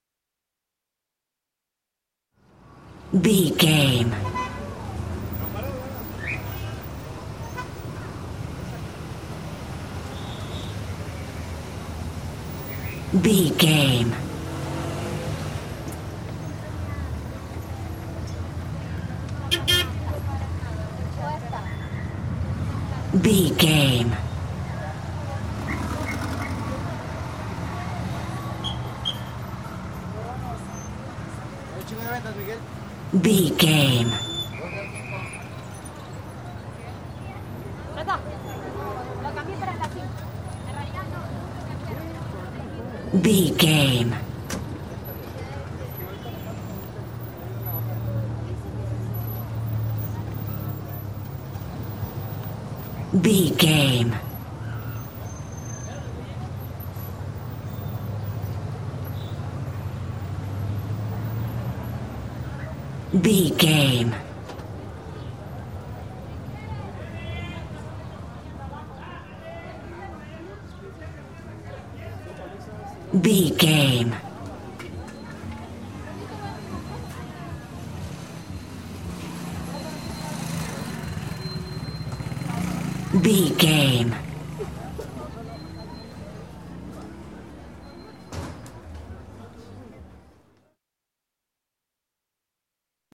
Mexico taxco street pedestrian vehicles
Sound Effects
urban
chaotic
ambience